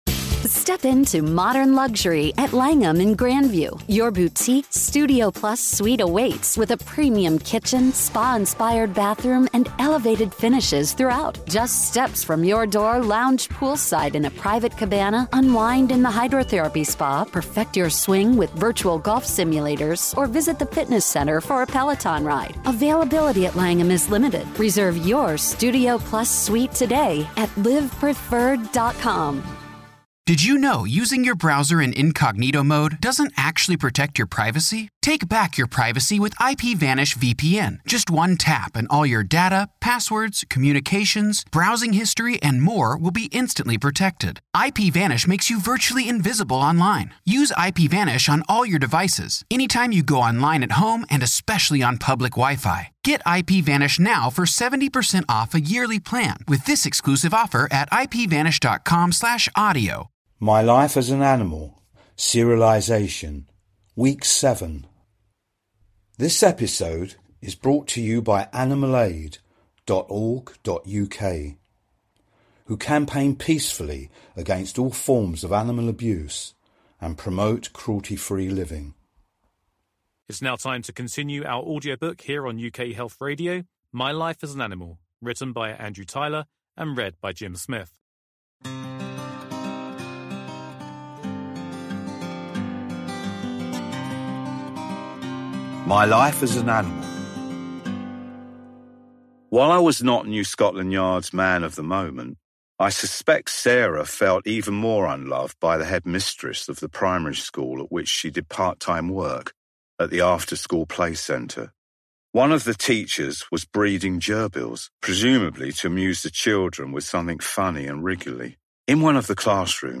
UK Health Radio is running its first ever Book serialisation!
It is beautifully written and sensitively voiced.